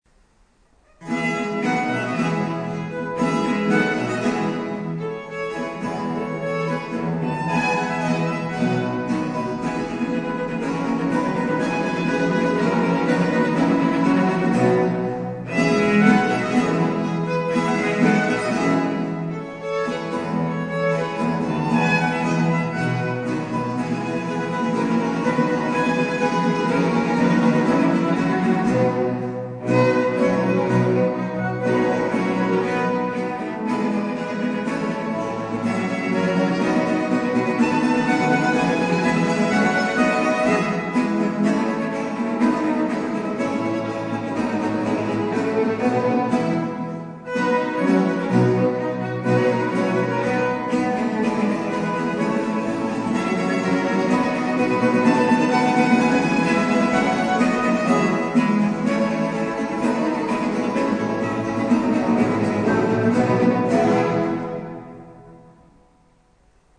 Konzertmitschnitt vom 29.03.2014 in Altötting